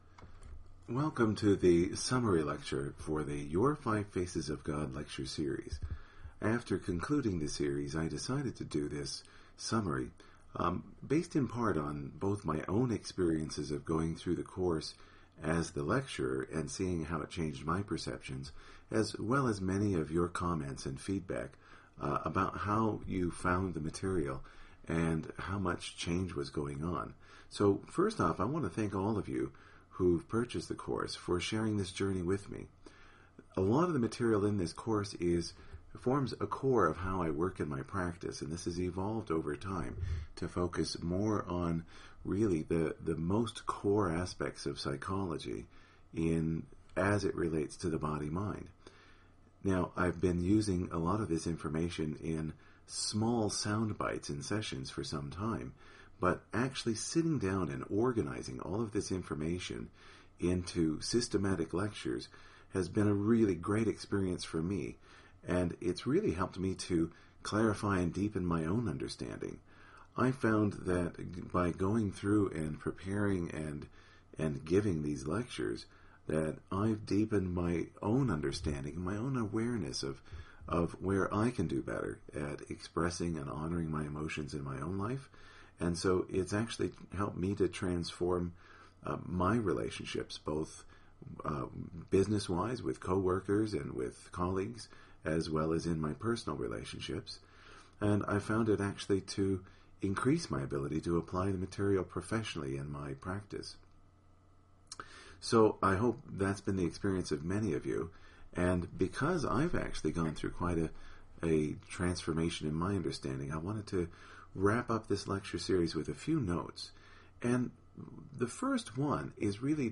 Your 5 Faces Summary Lecture – Accunect